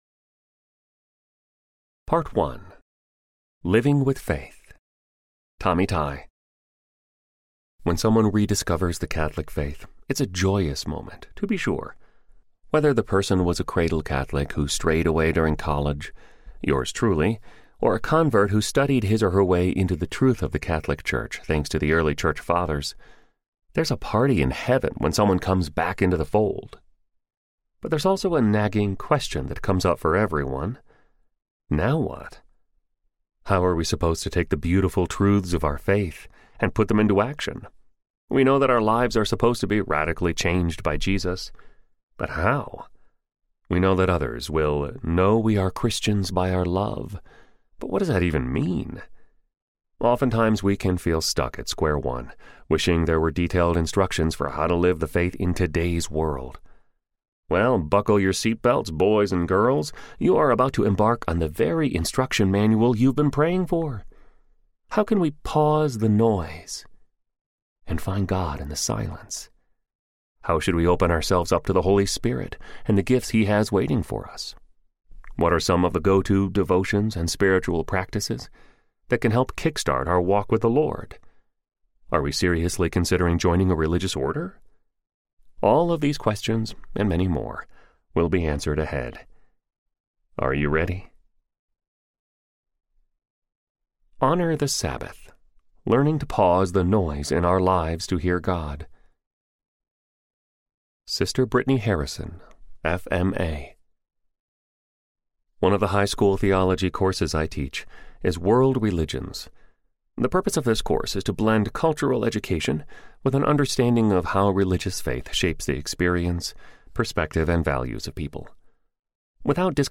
Catholic Hipster Audiobook
Narrator
5.0 Hrs. – Unabridged